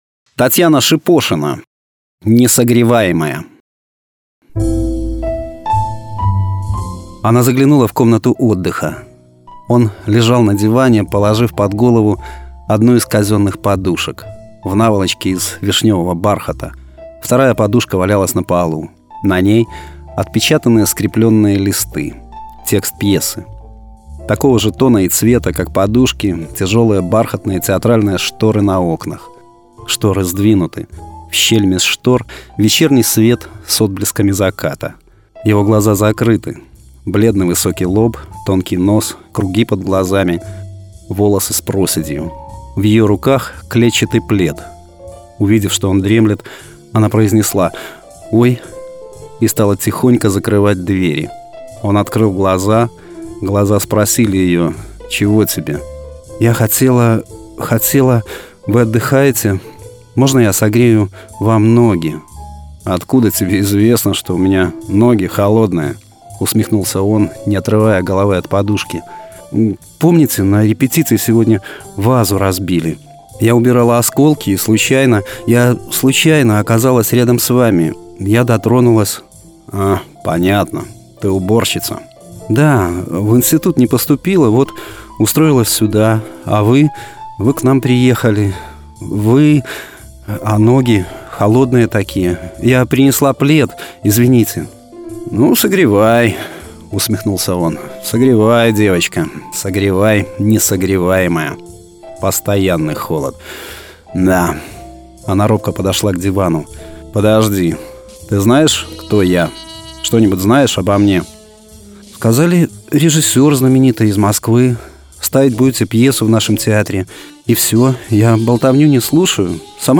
Аудиокниги
Аудио-Рассказы